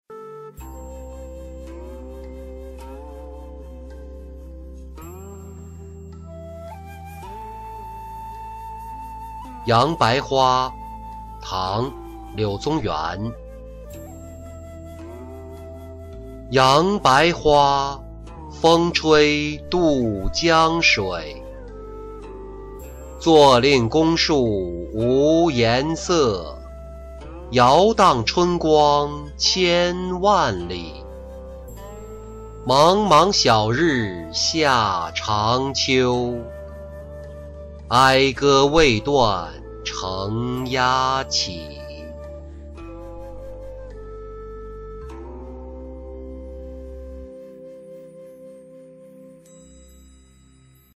杨白花-音频朗读